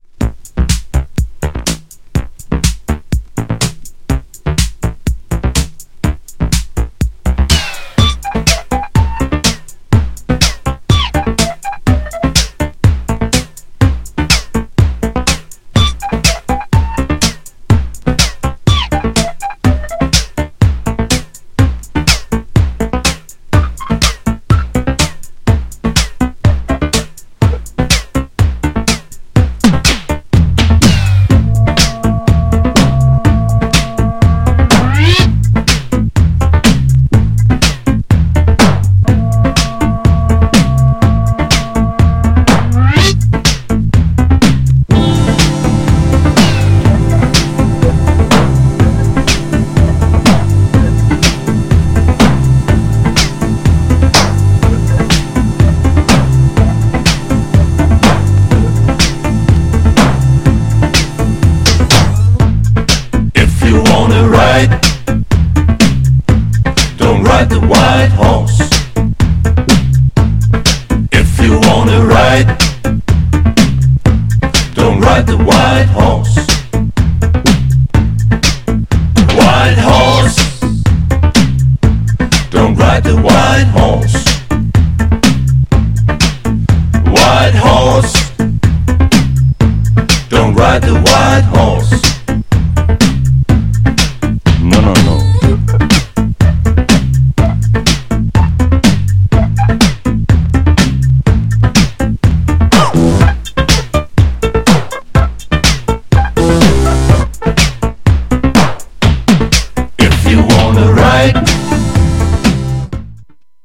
GENRE Dance Classic
BPM 81〜85BPM